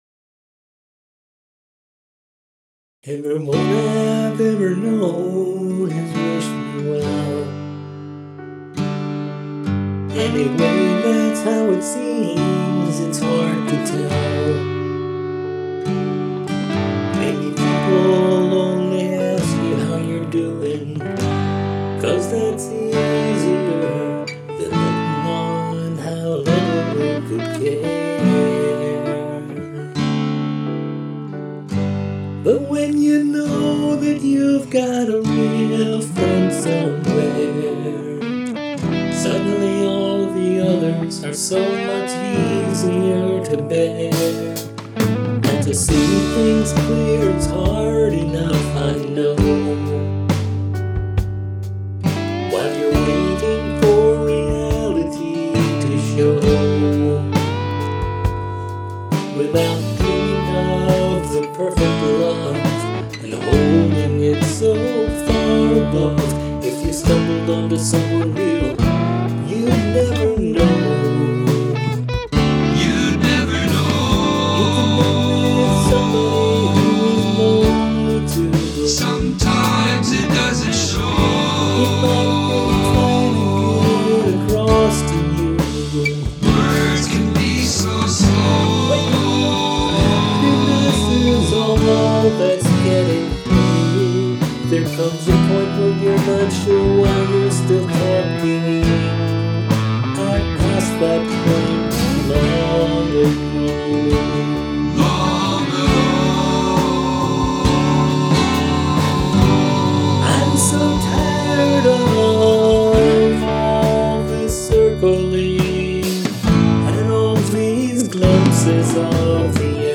Main vox Melodyne, compression, and EQ dressed it up a lot.
I started there and added grace notes, toms, and cymbals.